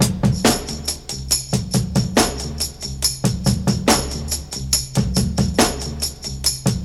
• 140 Bpm Breakbeat Sample B Key.wav
Free drum loop sample - kick tuned to the B note.
140-bpm-breakbeat-sample-b-key-PeR.wav